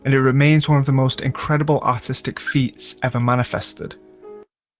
Vocal Sample